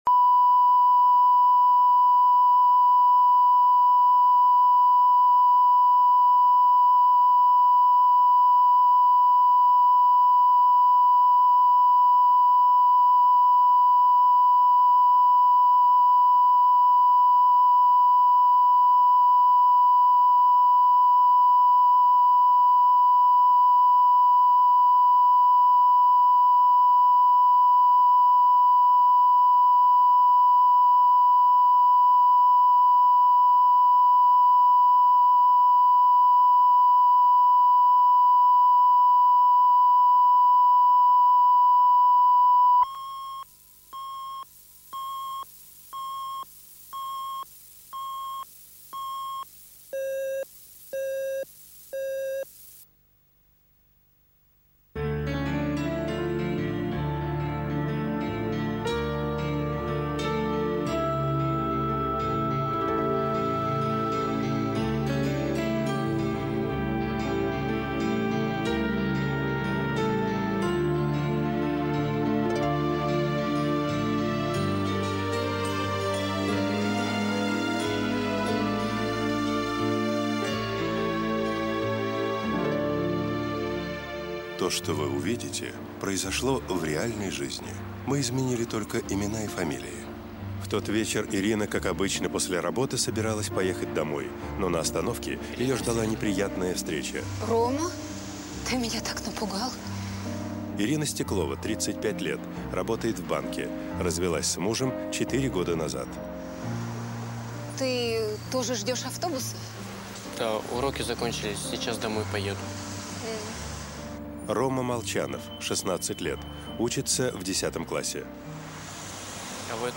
Аудиокнига Яблоко от яблони | Библиотека аудиокниг